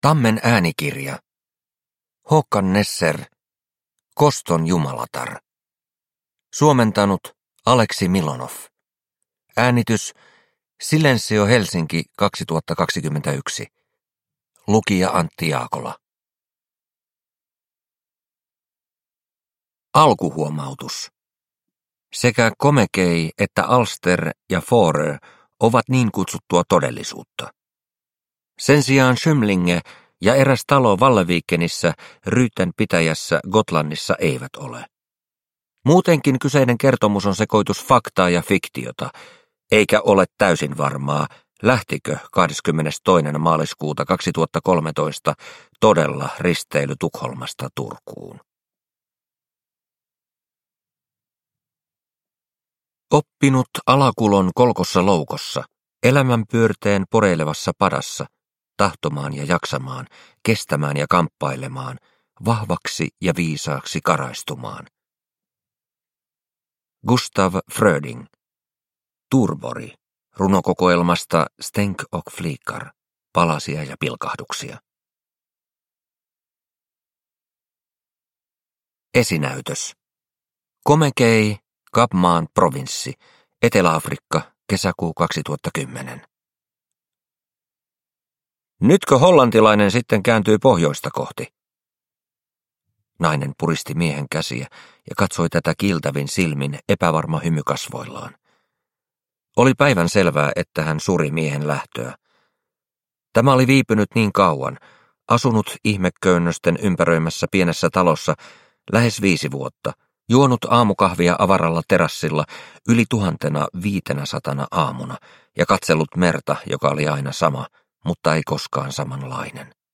Koston jumalatar – Ljudbok – Laddas ner